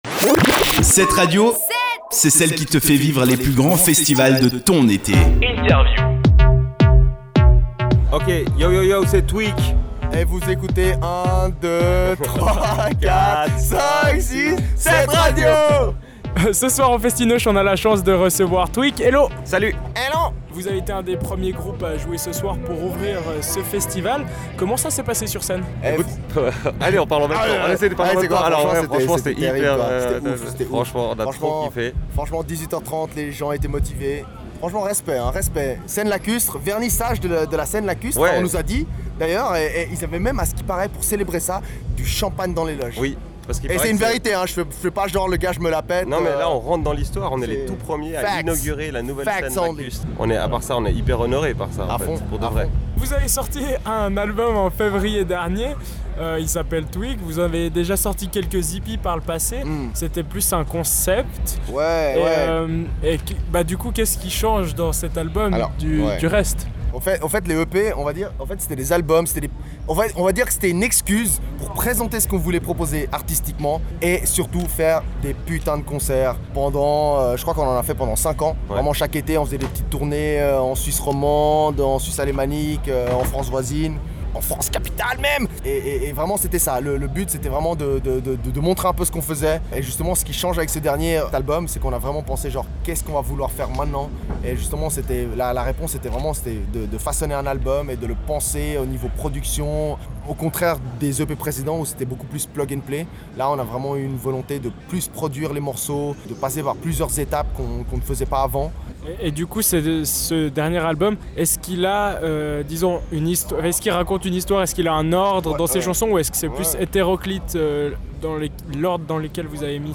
Festi’neuch 2017